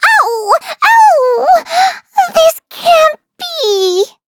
Taily-Vox_Dead.wav